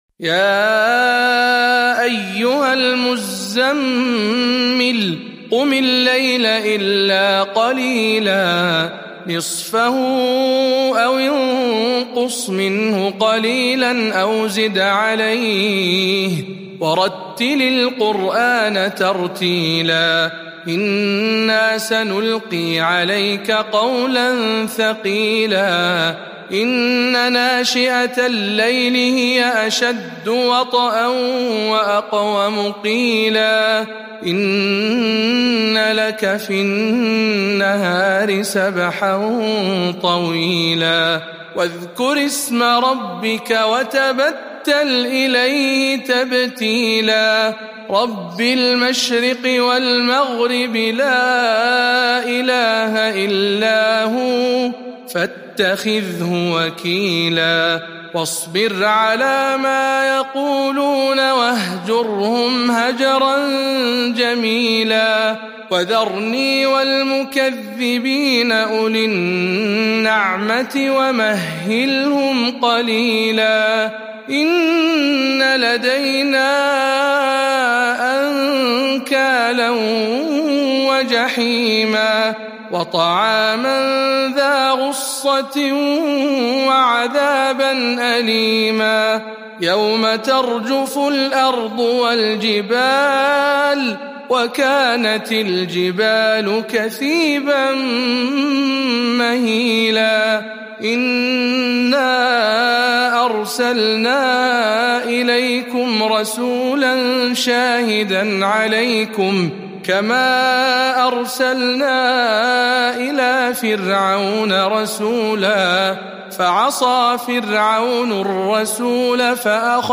سورة المزمل برواية شعبة عن عاصم - رمضان 1441 هـ